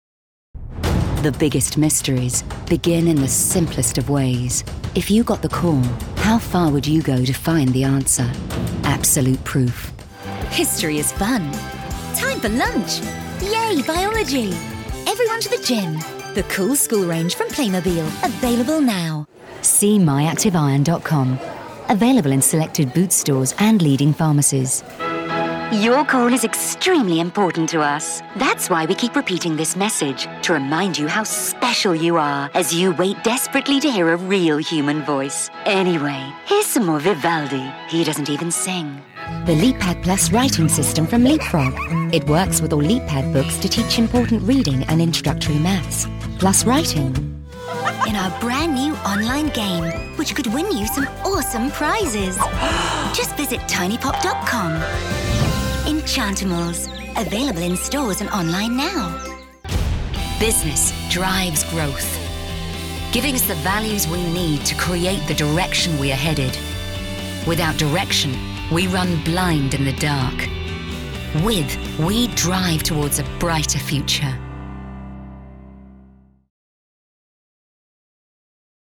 Warm, Trustworthy, Conversational, Characters, Comedy, Versatile
british rp | natural
COMMERCIAL 💸